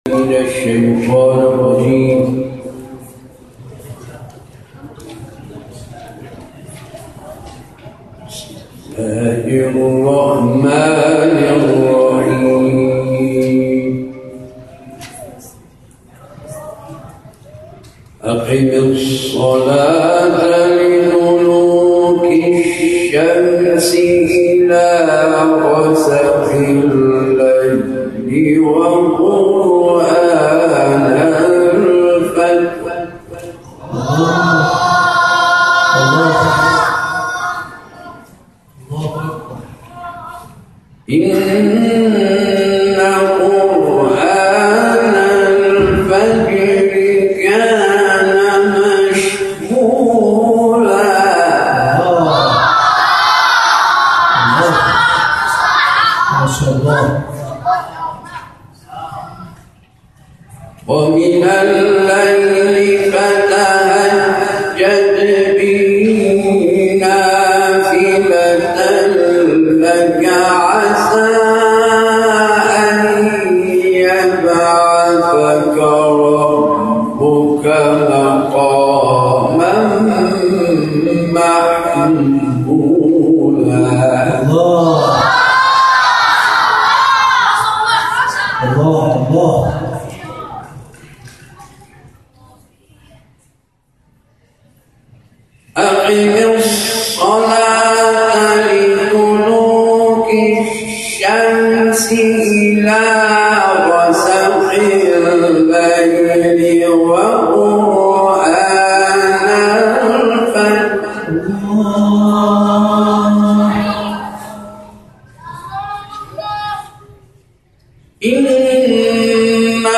گروه فعالیت‌های قرآنی: محفل انس با قرآن کریم روز گذشته، 23خردادماه در حسینیه هدایت تهران برگزار شد.